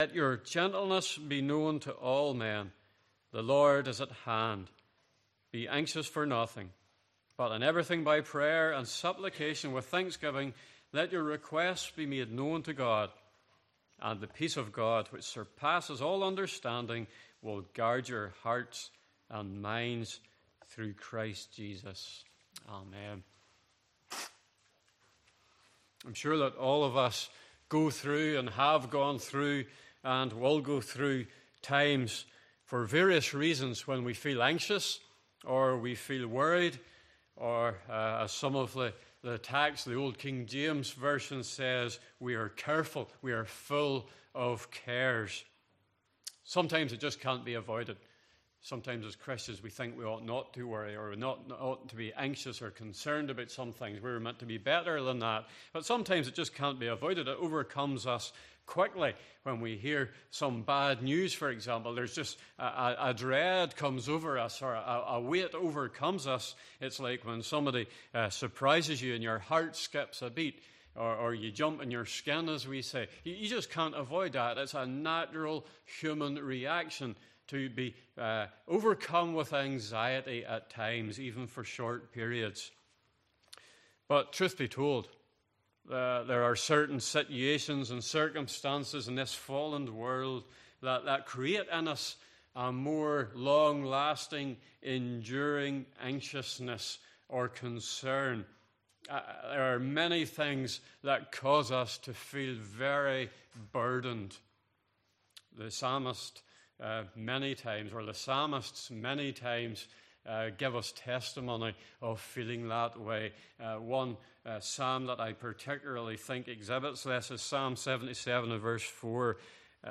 Passage: Philippians 4:5-7 Service Type: Evening Service